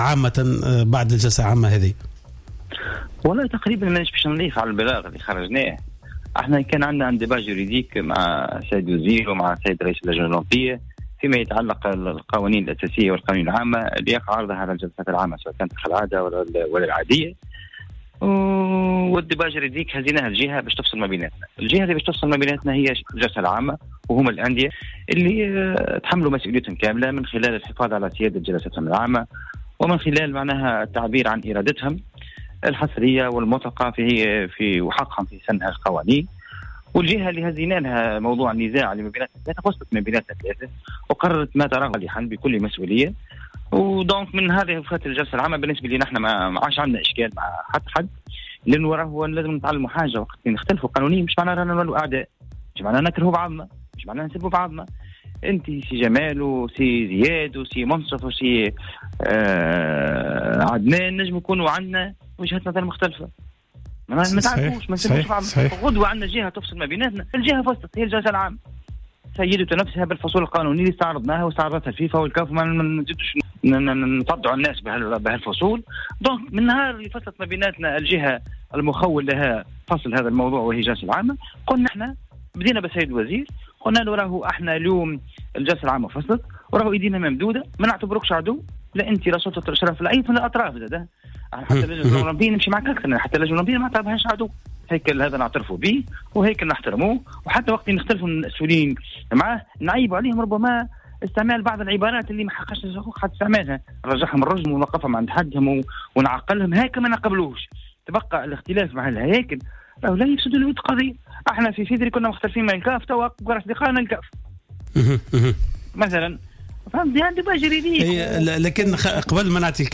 مداخلة في برنامج Cartes sur tables على جوهرة أف أم
وديع الجرئ : رئيس الجامعة التونسية لكرة القدم